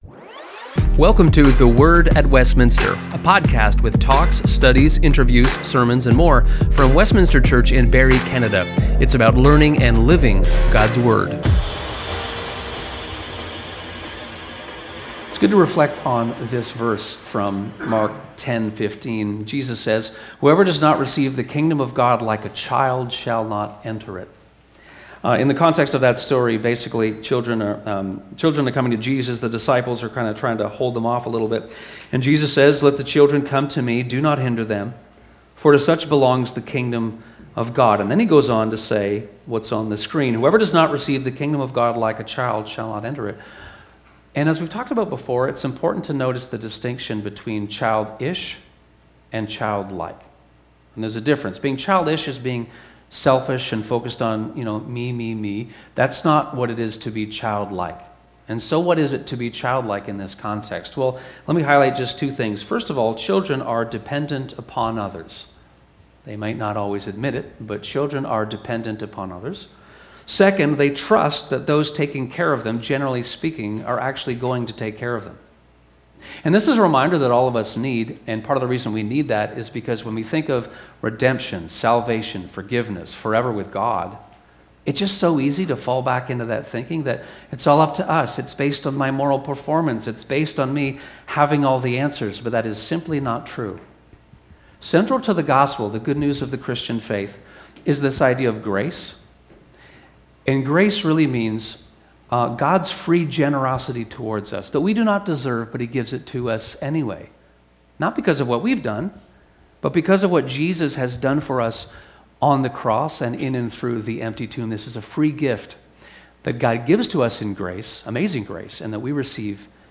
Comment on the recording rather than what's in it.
This short episode is a clip from the worship service on January 25, 2026.